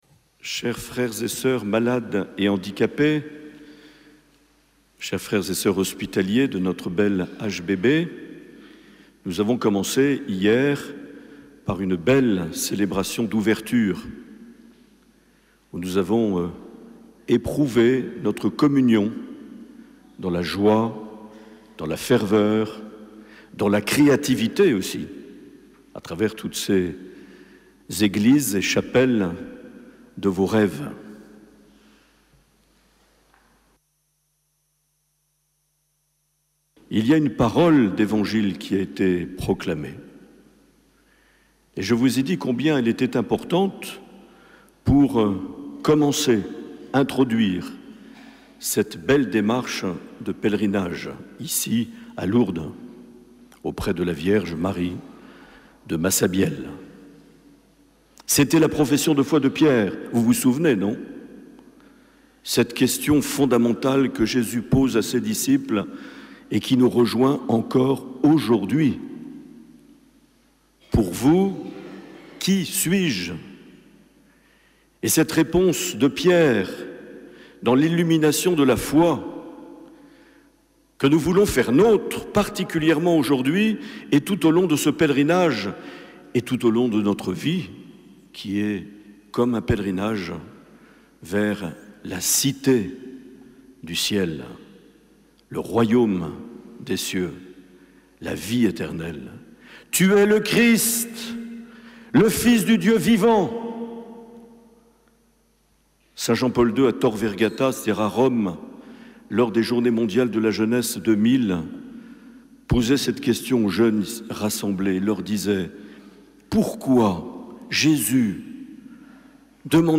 16 septembre 2023 - Lourdes - Messe avec l’HBB, les personnes malades ou porteuses d’un handicap
Accueil \ Emissions \ Vie de l’Eglise \ Evêque \ Les Homélies \ 16 septembre 2023 - Lourdes - Messe avec l’HBB, les personnes malades ou (...)
Une émission présentée par Monseigneur Marc Aillet